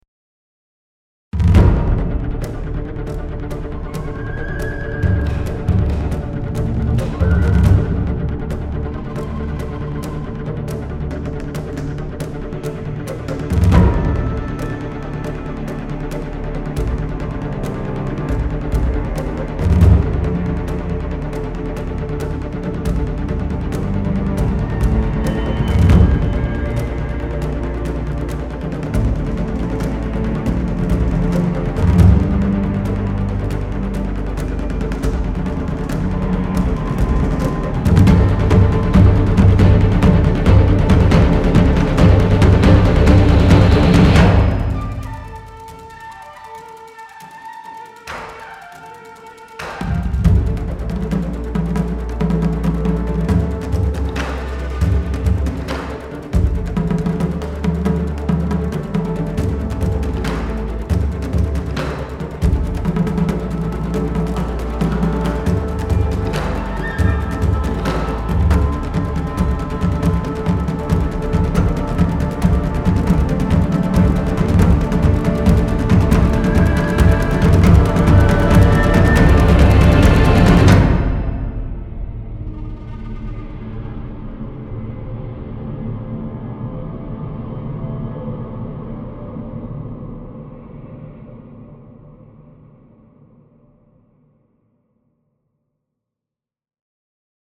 Strezov Sampling Taikos X3M是一款超级可玩的电影太鼓乐器，特别注重快速演奏和有机永恒的声音。
录制在Sofia Session Studio进行，我们还录制了以前的X3M库，以便进行平滑和轻松的分层。